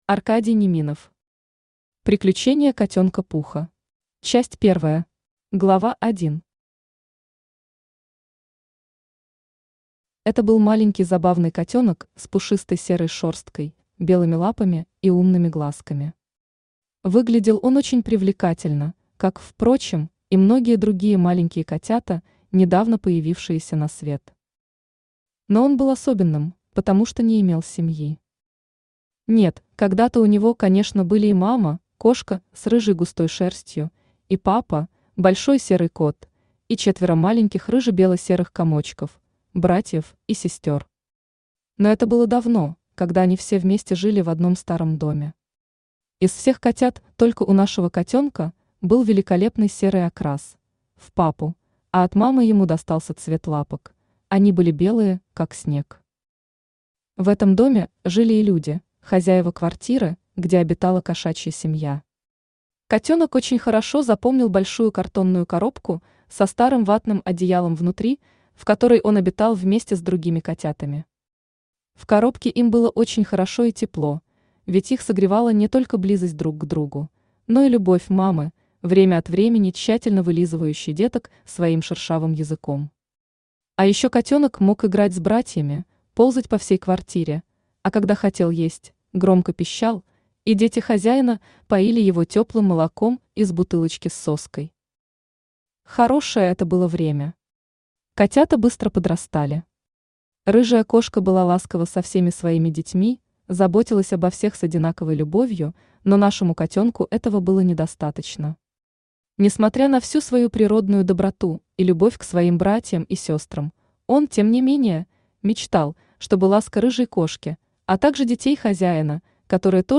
Аудиокнига Приключения Котёнка Пуха | Библиотека аудиокниг
Aудиокнига Приключения Котёнка Пуха Автор Аркадий Неминов Читает аудиокнигу Авточтец ЛитРес.